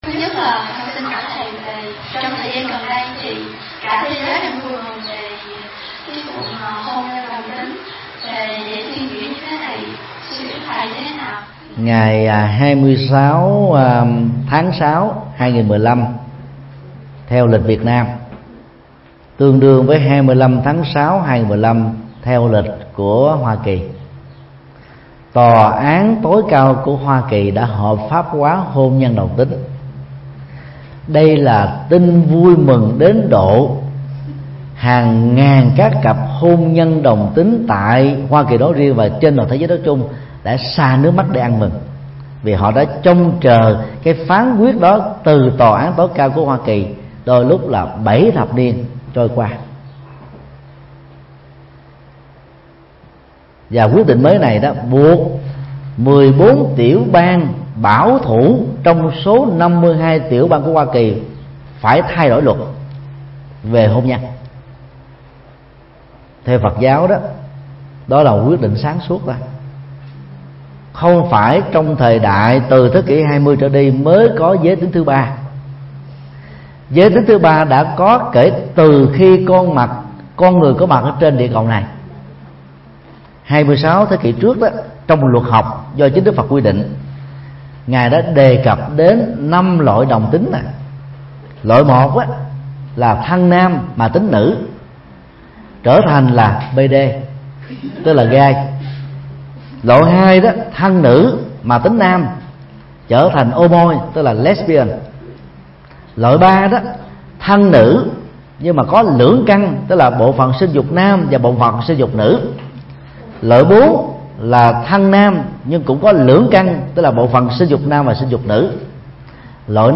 Mp3 Vấn đáp: Hôn nhân đồng tính – Thầy Thích Nhật Từ Giảng tai chùa Linh Phong,Thụy Sĩ,ngày 4 tháng 7 năm 2015